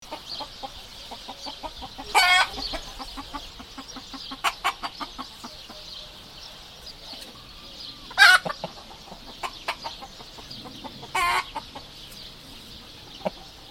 Sound Effects
Chicken Clucking Shortened